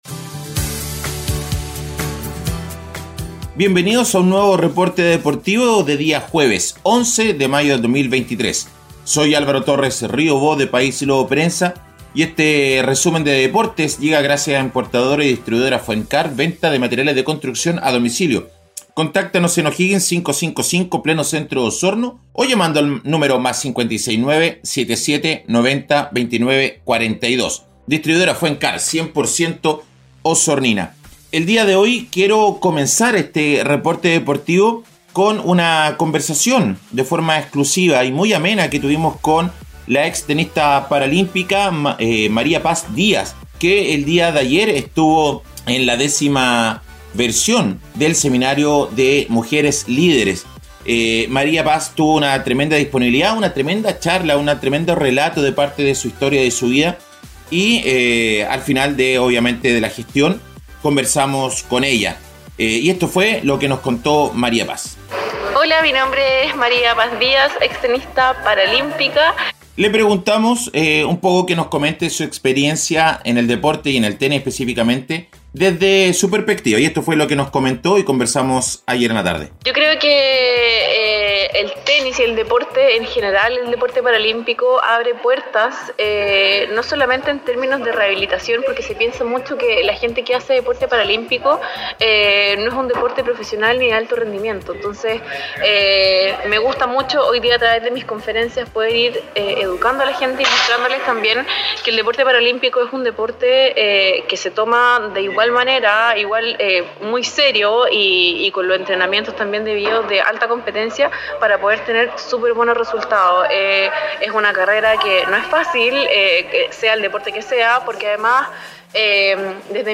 Reporte Deportivo 🎙 Podcast 11 de mayo de 2023